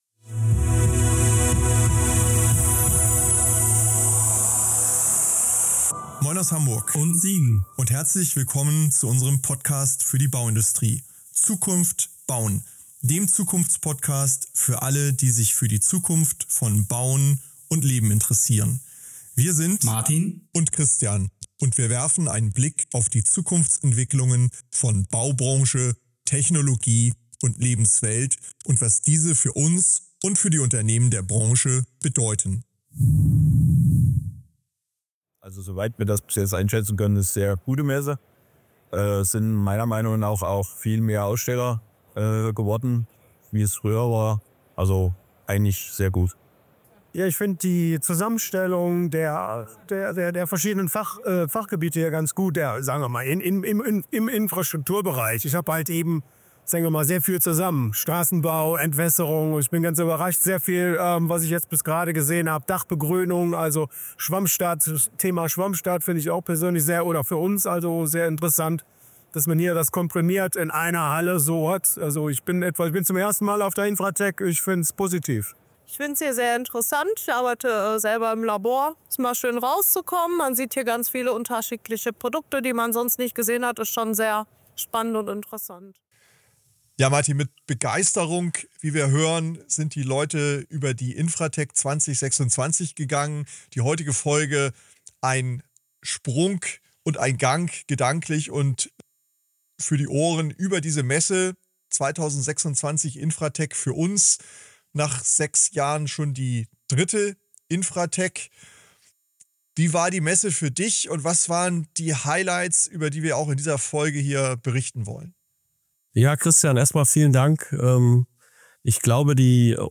Beschreibung vor 2 Monaten Die **InfraTech 2026** in Essen zeigte einmal mehr, wie groß das Interesse an zukunftsfähiger Infrastruktur ist – und wie viel Innovationskraft in der Branche steckt. In dieser Episode nehmen wir Sie mit auf einen akustischen Rundgang über die Messe, sprechen mit führenden Stimmen aus Bauindustrie, Politik und Verbänden – und ziehen ein persönliches Fazit zur Stimmung, den Herausforderungen und Perspektiven.